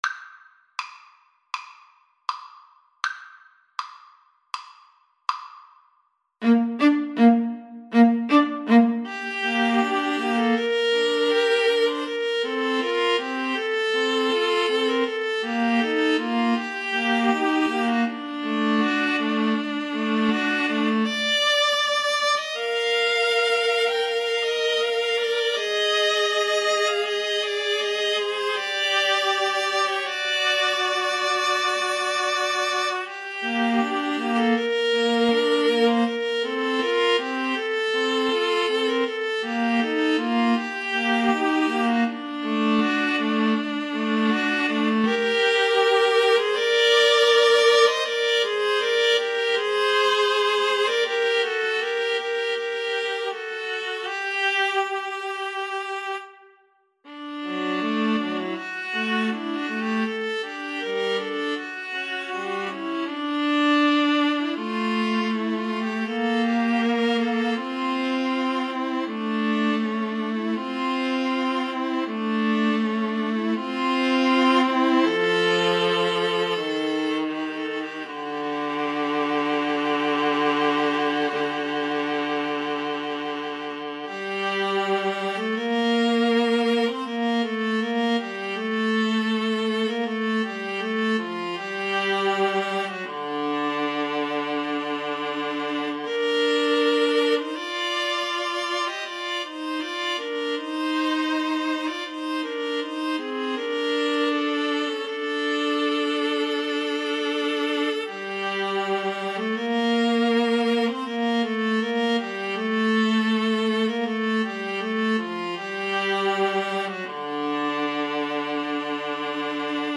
=100 Andante
Classical (View more Classical Viola Trio Music)